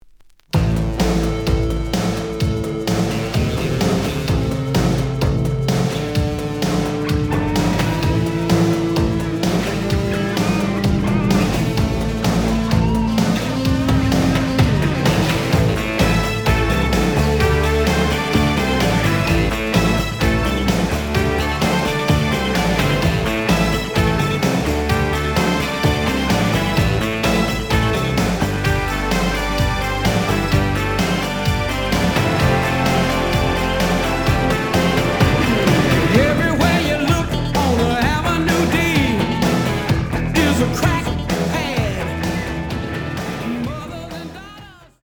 The audio sample is recorded from the actual item.
●Genre: Funk, 80's / 90's Funk